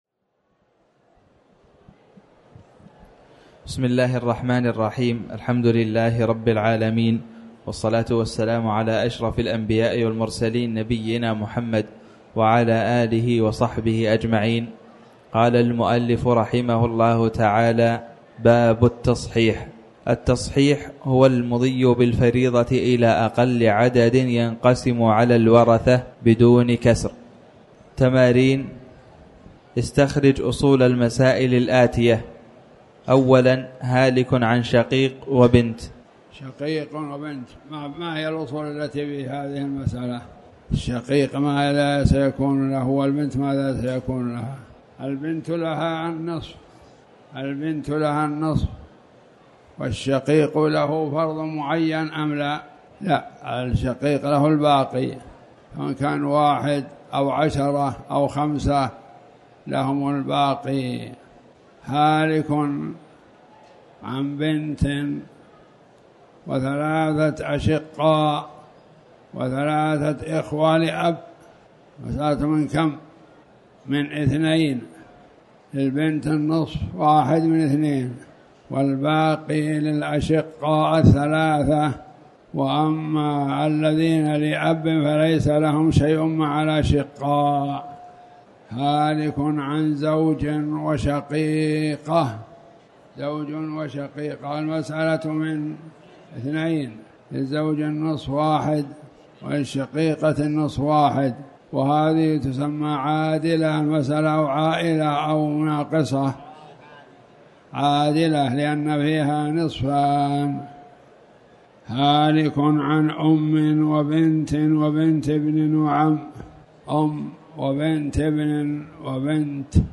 تاريخ النشر ١٨ صفر ١٤٣٩ هـ المكان: المسجد الحرام الشيخ